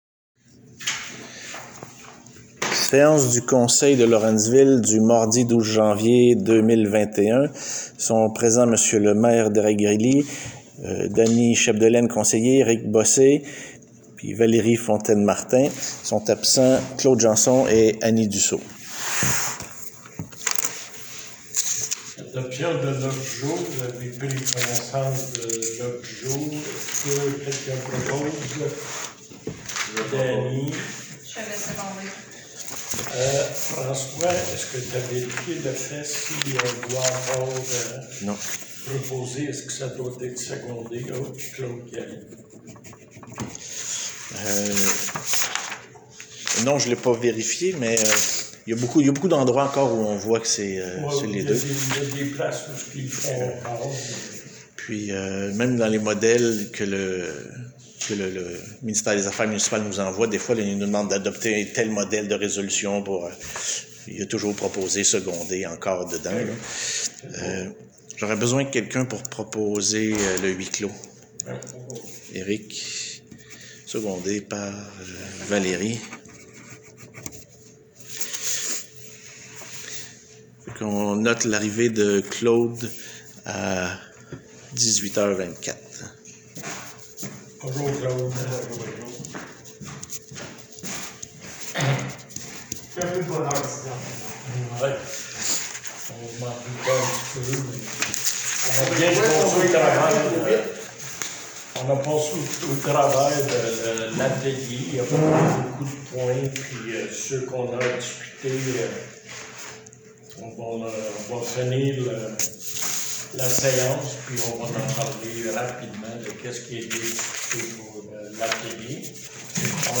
Enregistrements des séances du conseil municipal de la municipalité de Lawrenceville en Estrie